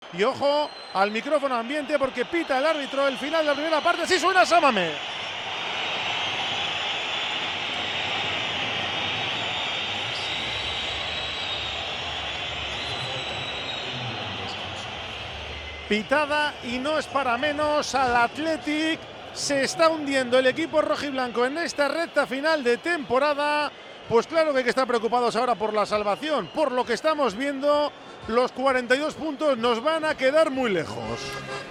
Así fue la pitada de San Mamés al Athletic
La grada estalló ante otro ejercicio de impotencia futbolística
Gran parte de la grada de San Mamés estalló contra el Athletic al llegar al descanso contra el Villarreal. El segundo gol visitante, tras el fallo de Lekue, y la mala imagen del equipo, provocó el enfado de la afición zurigorri hasta tal punto que despidió con una sonora pitada a los jugadores al descanso.
PITADA-ATHLETIC-VILLARREAL.mp3